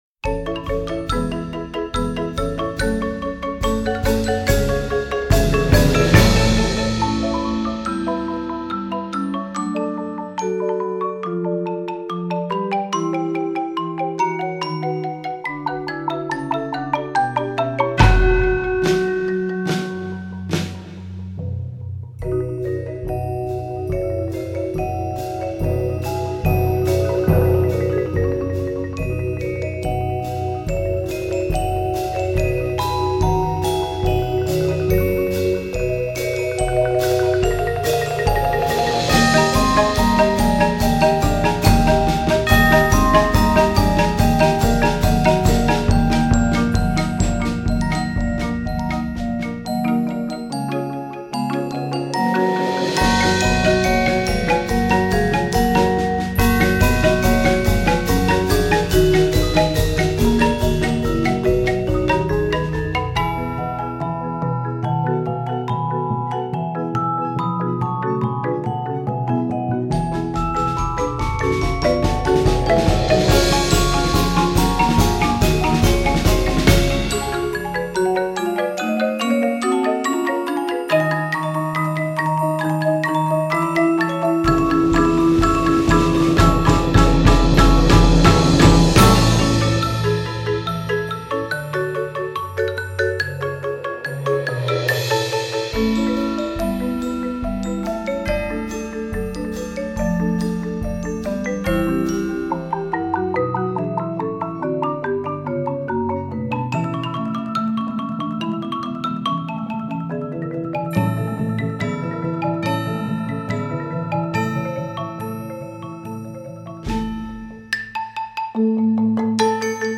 Voicing: Concert